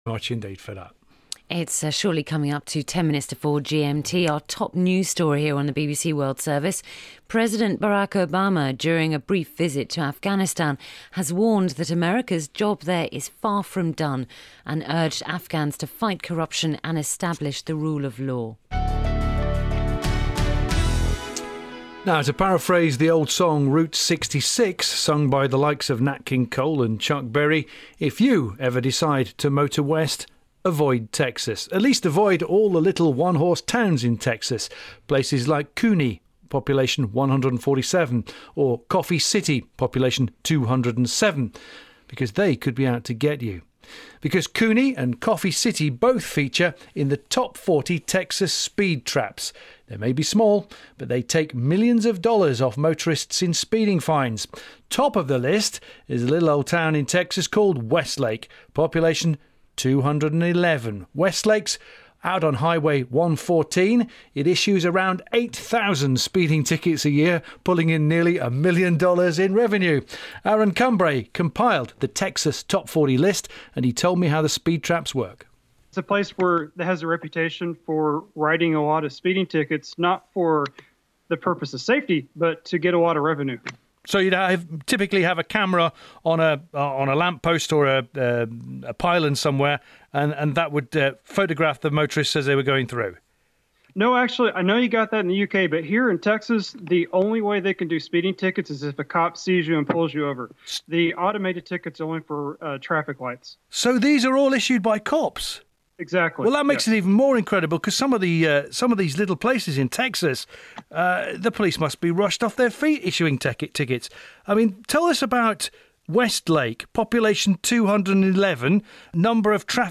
We did it over Skype.
If you listen to the interview, you’ll hear disturbances in the audio. I guess I leaned too closely or breathed into it?
The interview.